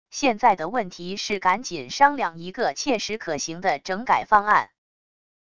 现在的问题是赶紧商量一个切实可行的整改方案wav音频生成系统WAV Audio Player